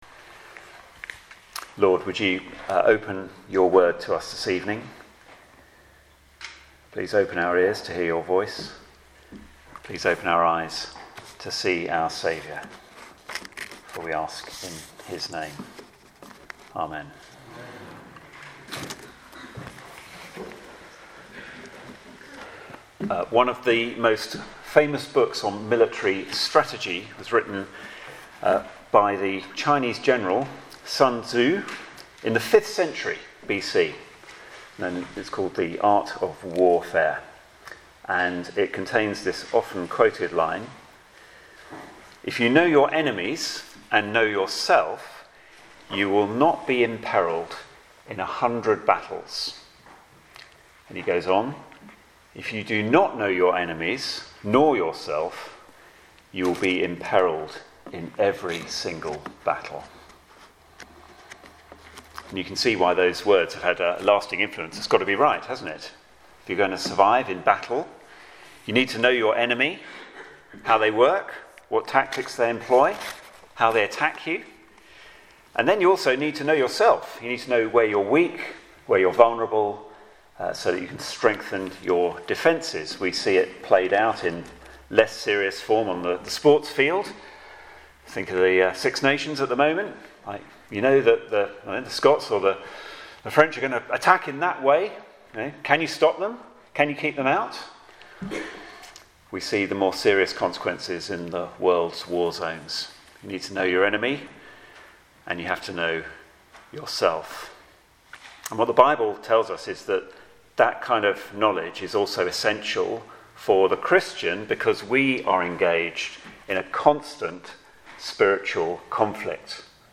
Media for Sunday Evening on Sun 02nd Mar 2025 18:00
Passage: Ephesians 6:10-18 Series: Spiritual battles Theme: Sermon